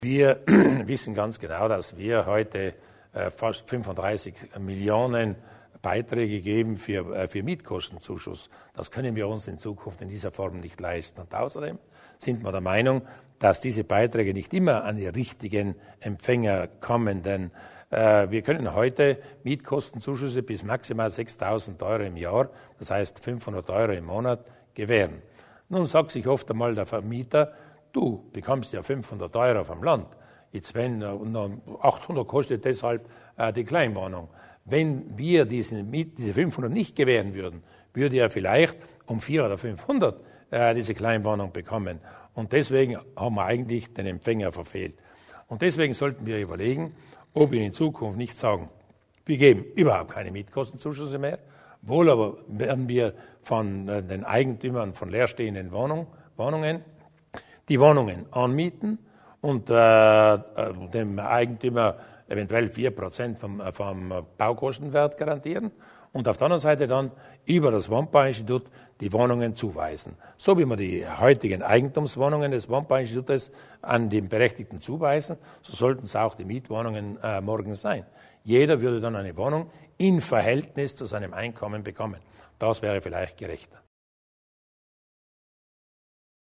Landeshauptmann Durnwalder zu den möglichen Änderungen in Sachen Landeswohngeld